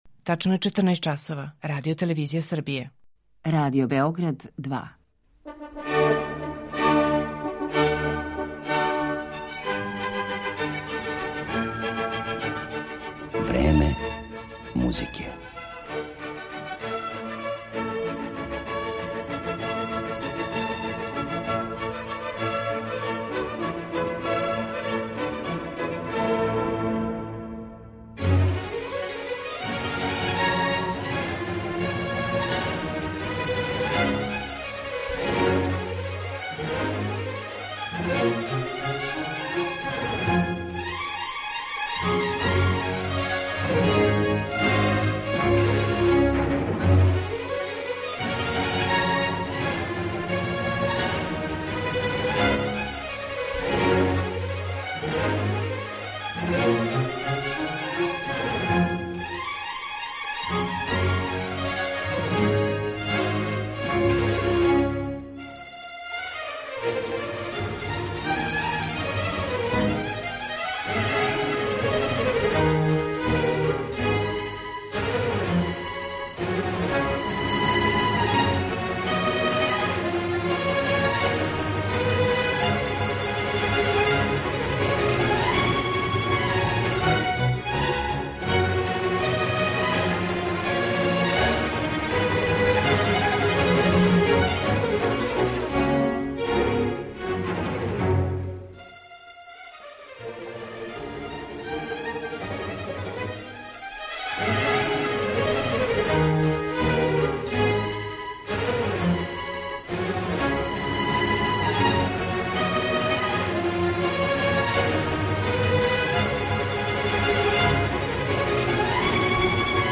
У данашњем Времену музике представићемо га особеним интерпретацијама дела Жан-Филипа Рамоа, Волфганга Амадеуса Моцарта, Петра Чајковског, Игора Стравинског и Густава Малера.